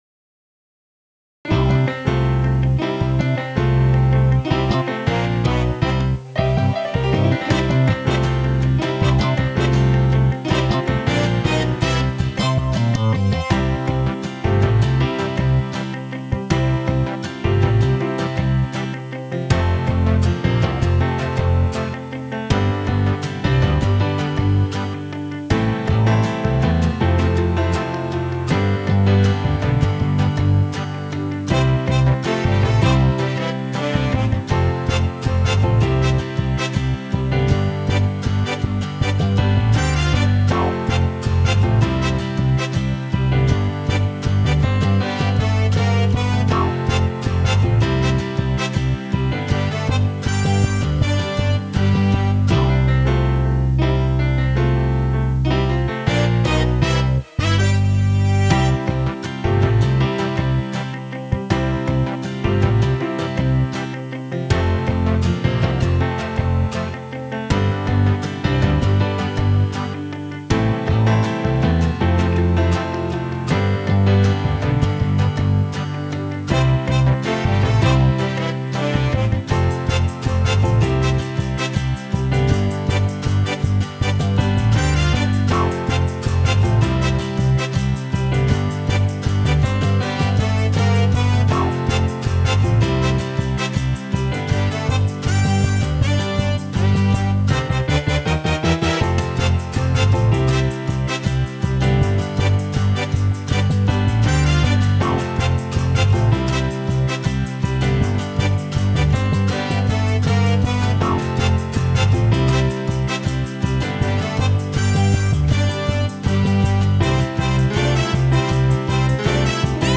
MUSIC AUDIO ONLY
PreparingAPlace_TRAX_Traditional.wav